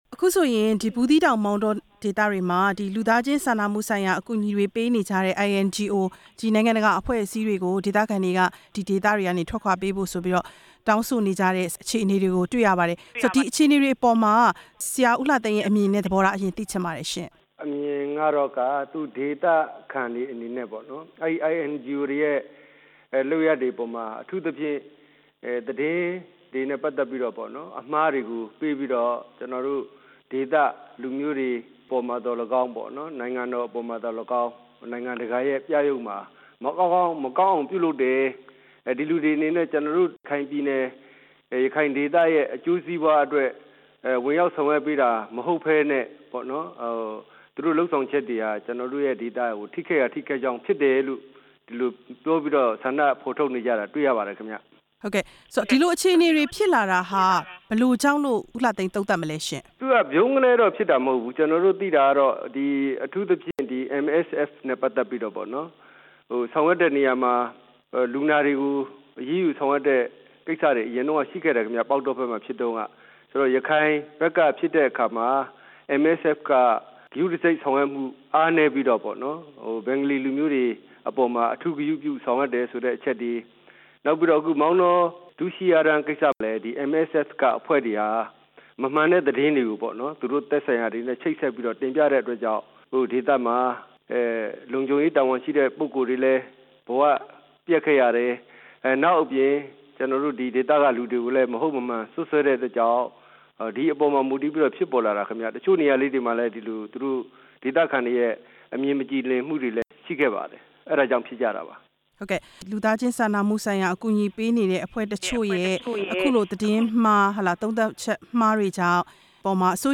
ပြည်နယ်ဥပဒေချုပ် ဦးလှသိန်းနဲ့ မေးမြန်းချက် နားထောင်ရန်